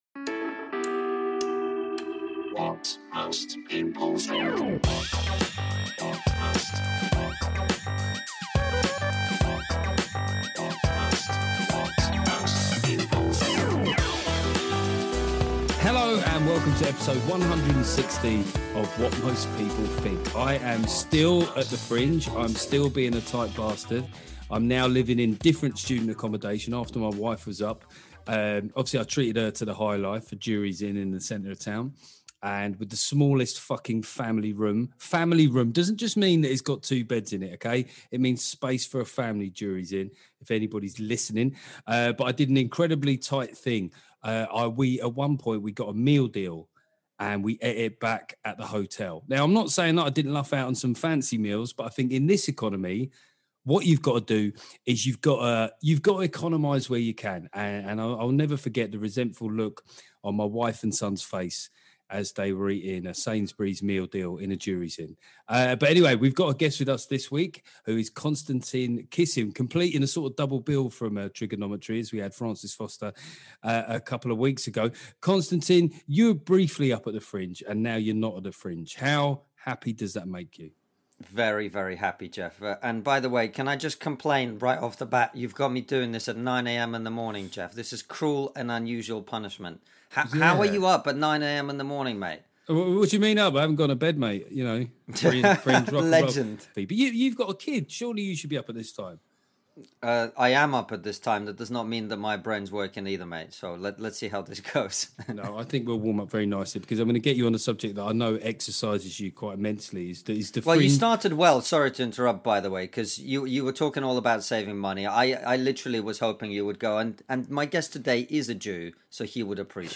I fully stand by my Barack Obama impression.